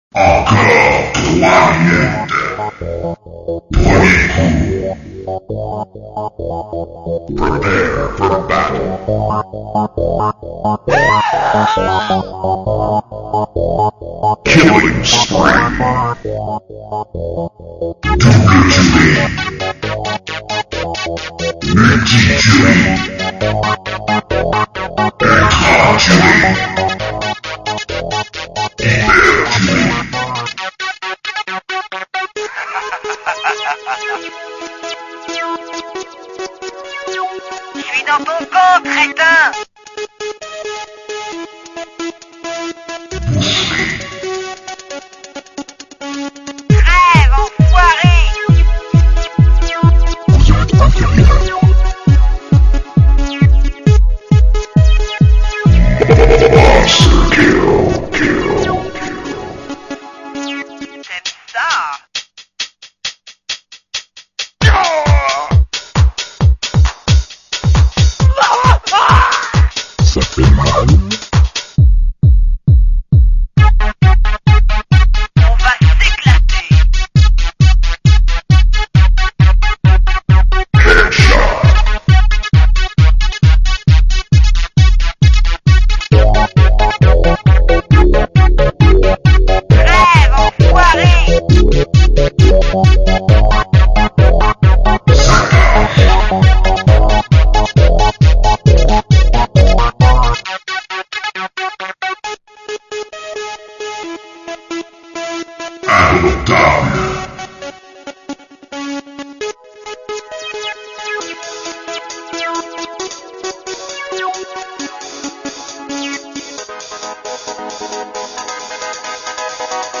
Mp3 remix: